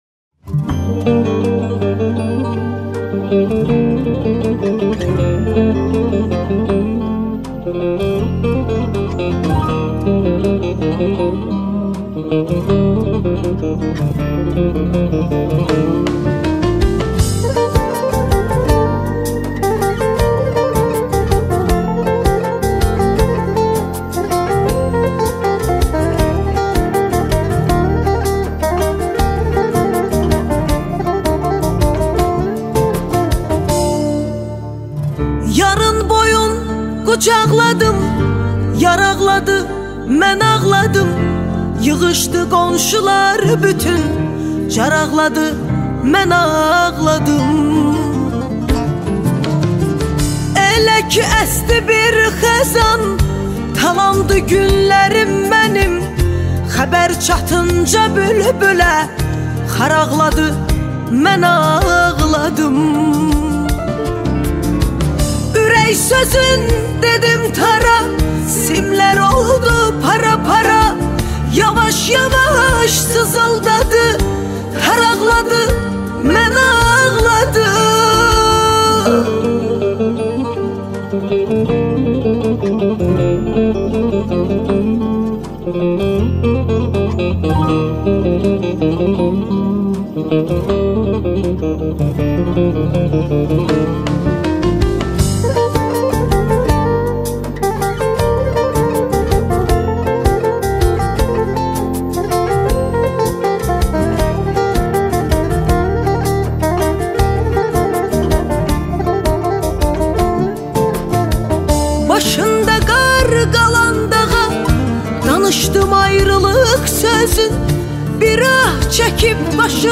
آهنگ آذری